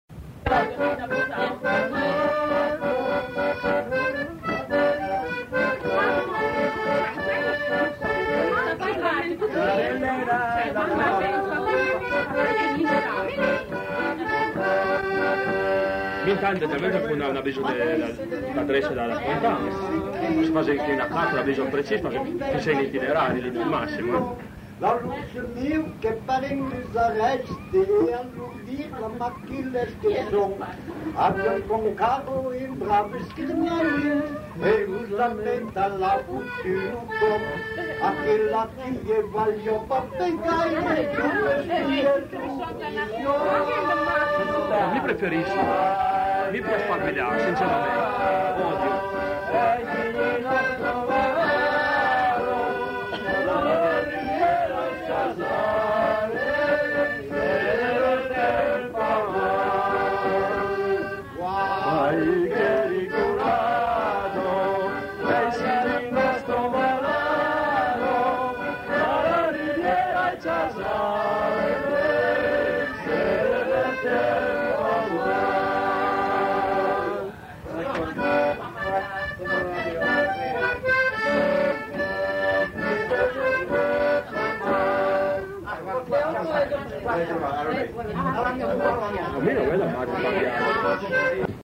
Aire culturelle : Val Varaita
Lieu : Bellino
Genre : chanson-musique
Type de voix : voix mixtes
Production du son : fredonné
Instrument de musique : accordéon diatonique
Danse : valse